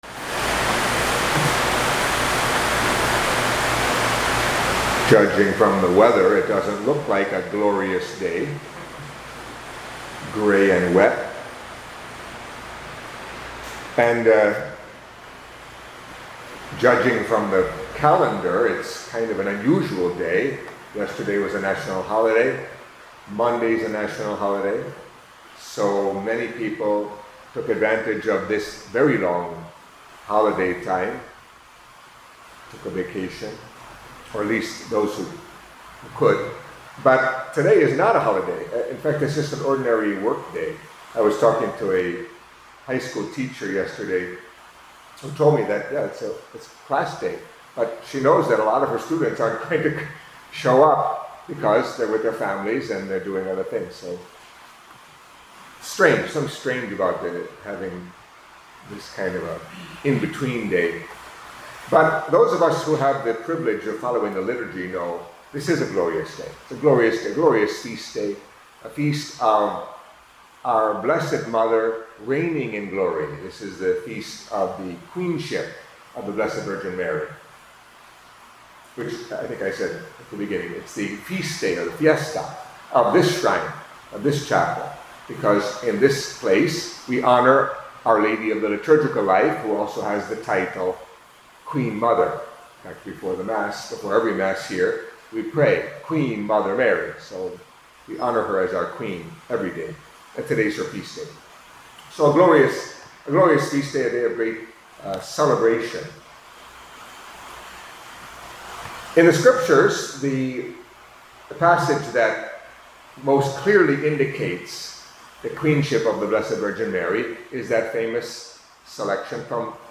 Catholic Mass homily for Friday of the Twentieth Week in Ordinary Time